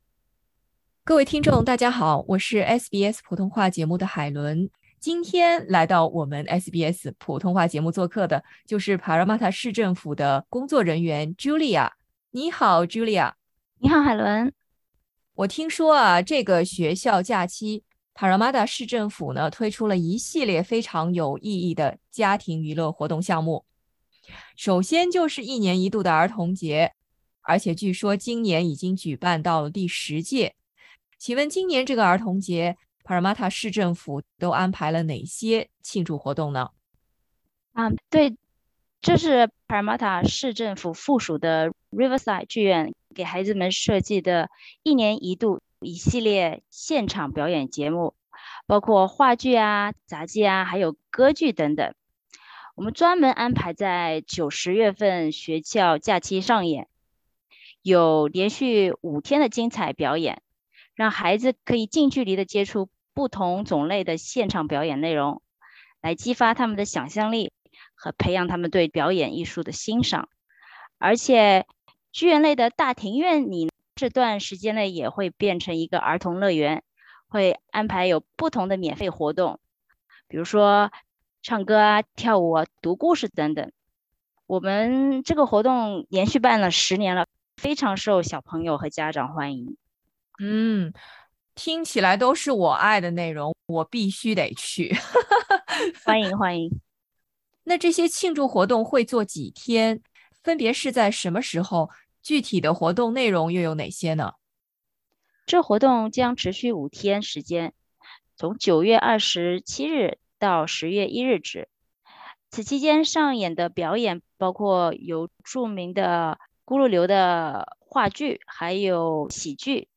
SBS 普通话电台 View Podcast Series